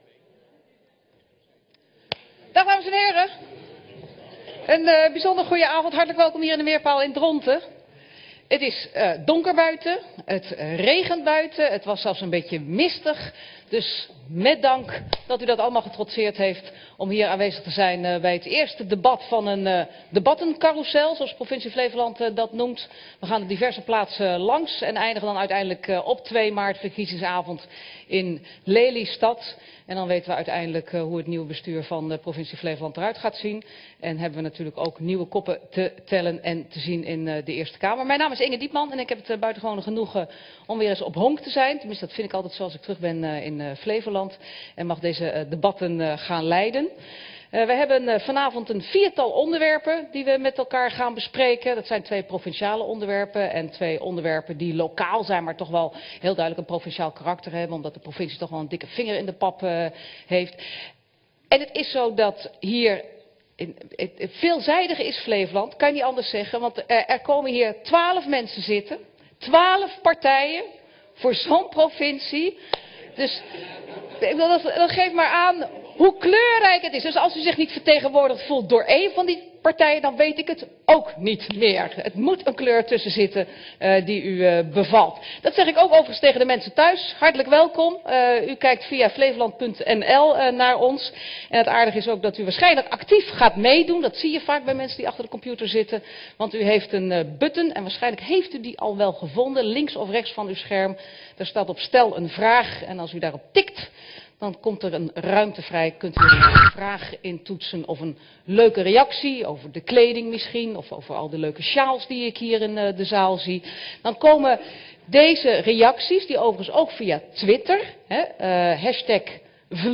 Verkiezingsdebat 01 februari 2011 15:30:00, Provincie Flevoland
Download de volledige audio van deze vergadering